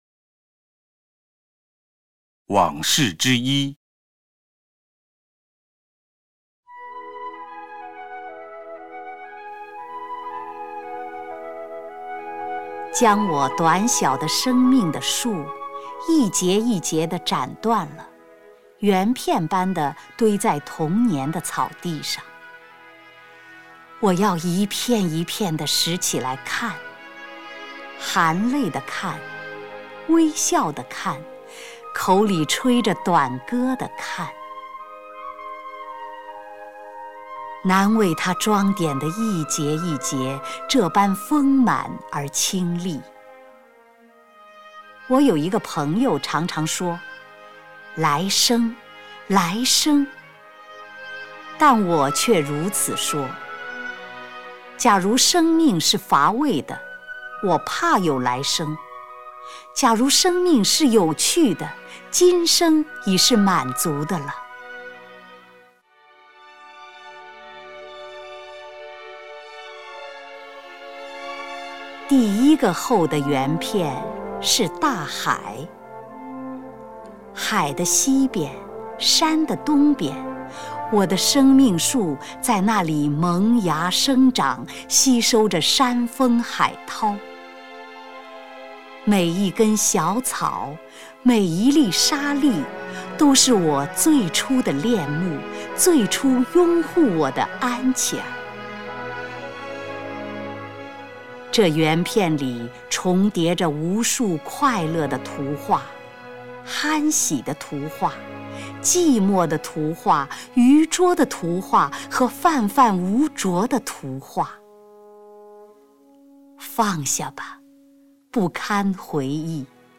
[2/8/2010]丁建华配乐朗诵冰心散文《往事（一）》（192K MP3）
朗诵 丁建华